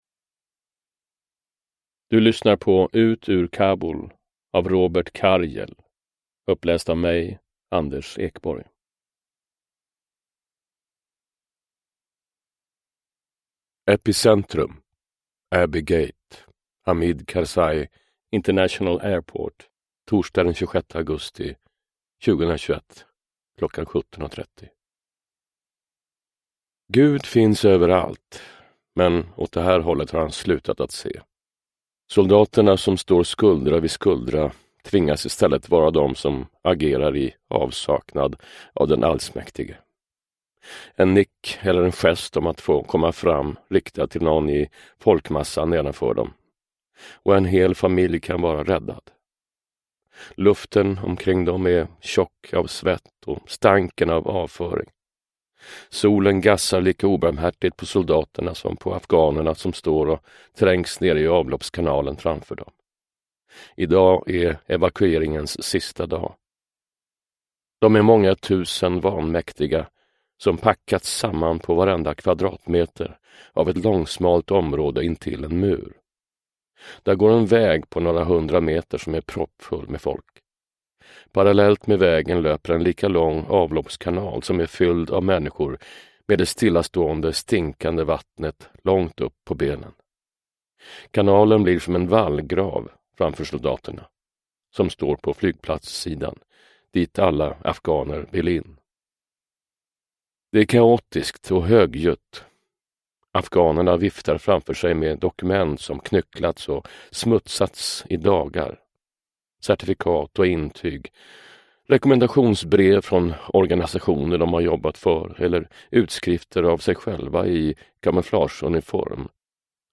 Ut ur Kabul : den svenska evakueringen från Afghanistan – Ljudbok – Laddas ner
Uppläsare: Anders Ekborg